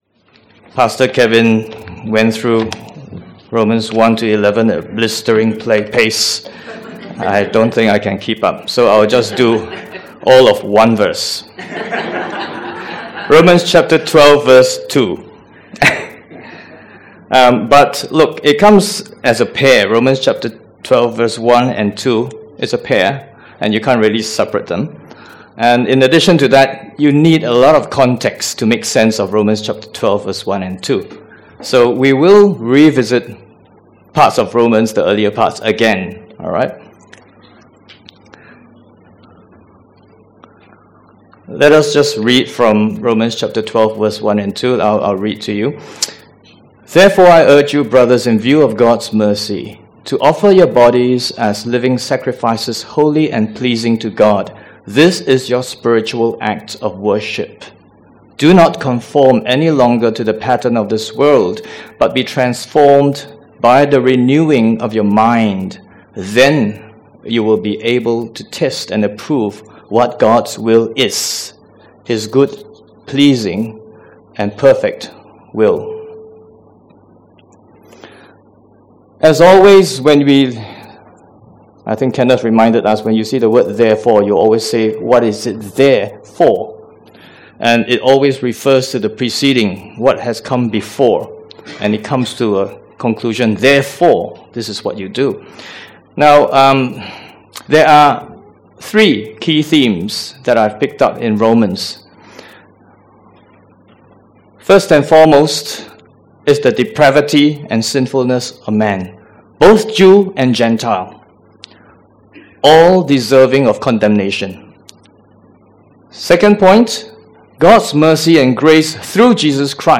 Categories Sermon Tags Rom 12:1-2